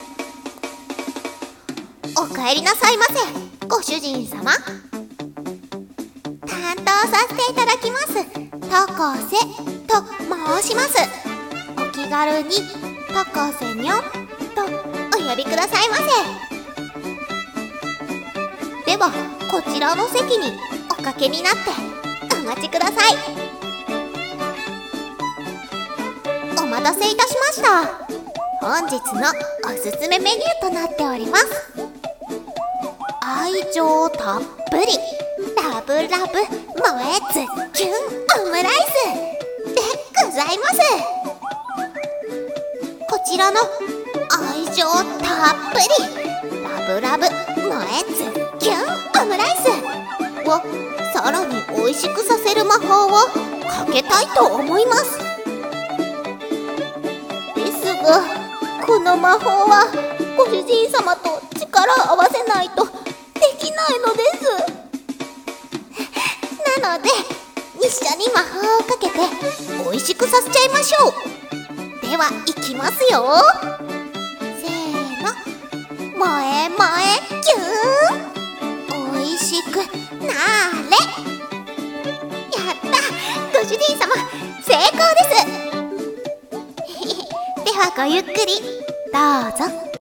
【声劇朗読】メイド喫茶